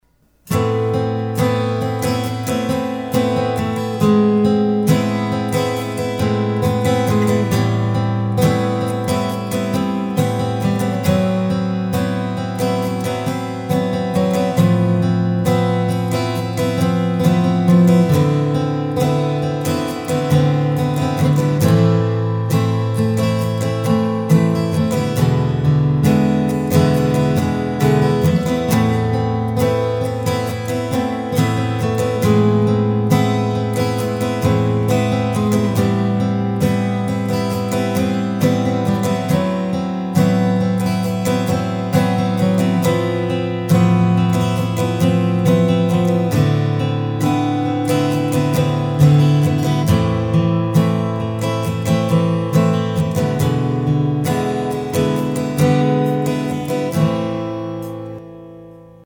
Home/Studio Recordings